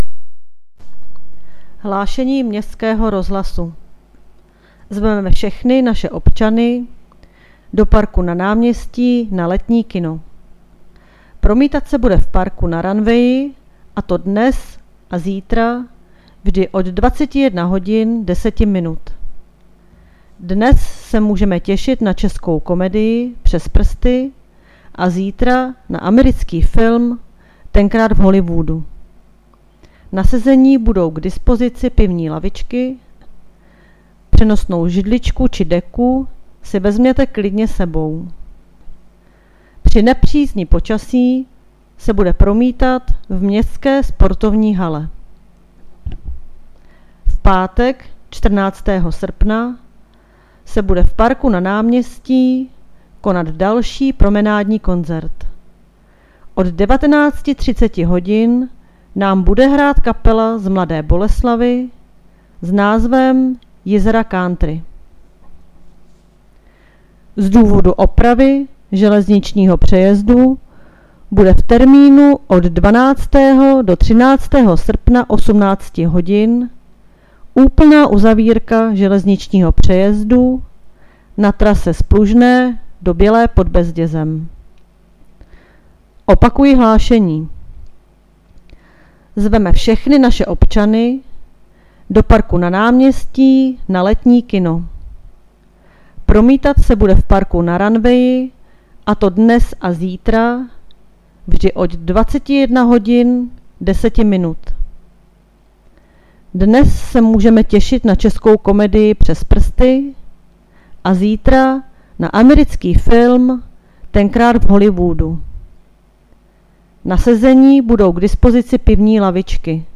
Hlášení městského rozhlasu 12.8.2020